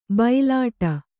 ♪ bailāṭa